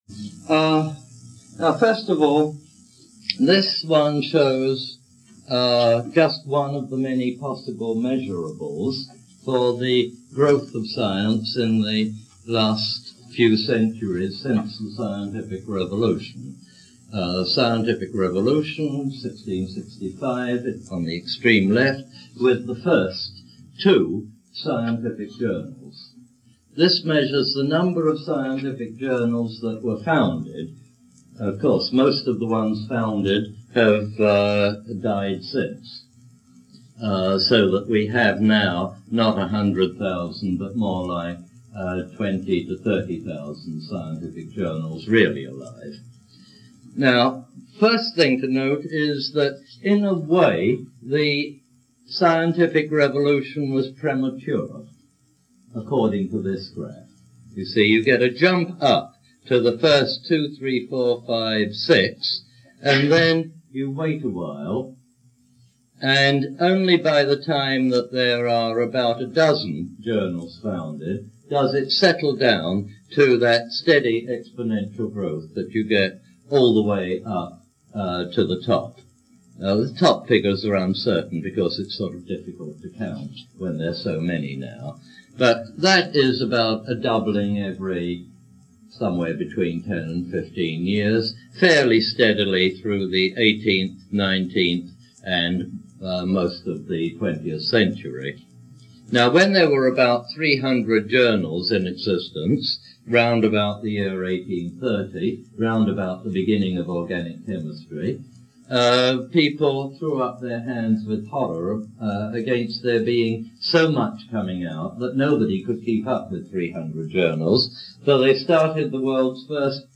Derek de Solla Price "Neolithic to Now" Lecture #21, Yale 1976.